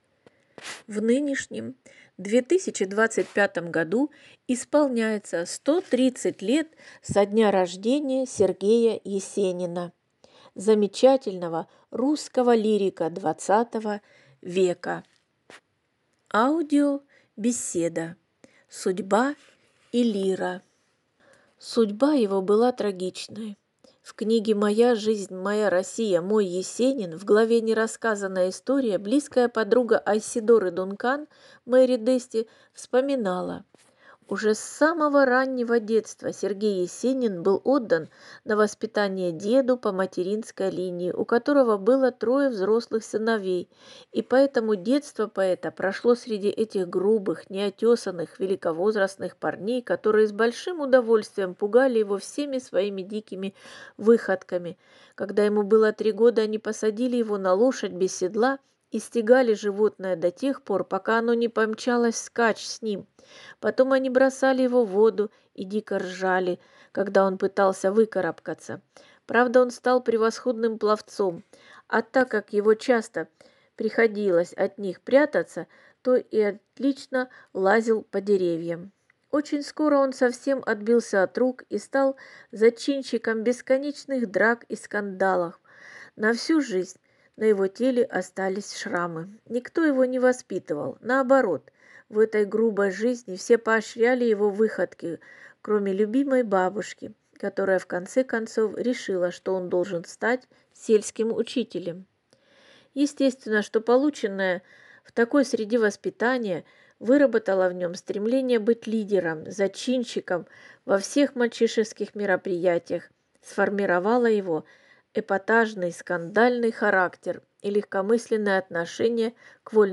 Ростовский пункт выдачи подготовил аудиобеседу « Судьба и лира », посвященную 130-летию замечательного русского лирика ХХ века С.А. Есенина. Беседа напомнит читателям о детских и юношеских годах поэта, прозвучат поэтические строки, наполненные деревенской теплотой, нежной любовью к матери и родному краю, раскрывающие красоты «страны березового ситца».